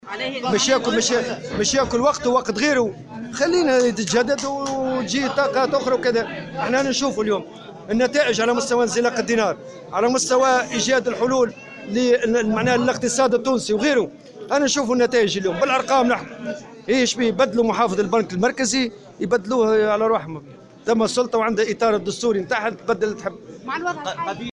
على هامش أشغال مؤتمر الجامعة العامة للنفط والمواد الكيمياوية